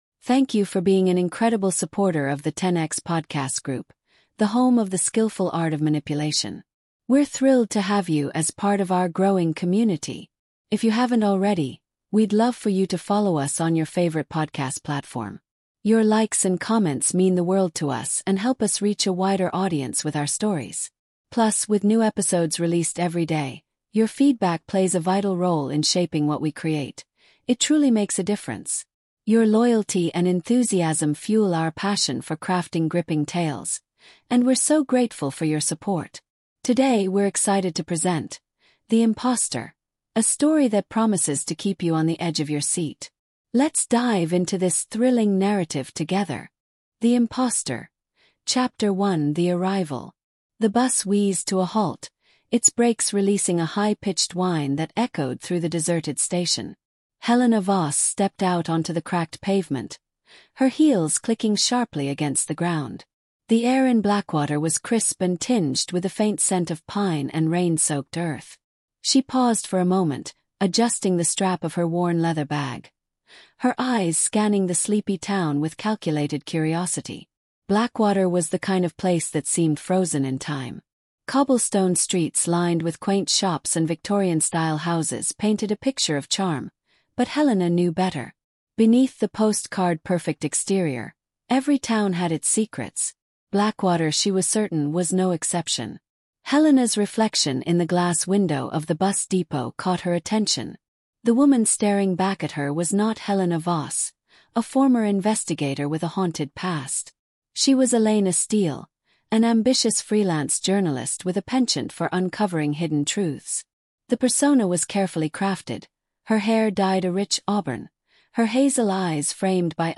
The Imposter is a gripping storytelling podcast that masterfully blends manipulation, thriller, and suspense. Follow Helena Voss, a determined woman who assumes a false identity to infiltrate the life of the enigmatic psychologist Dr. Victor Carlisle. As Helena unravels his sinister secrets, she discovers a web of lies, control, and deception that challenges her understanding of trust, identity, and her own past.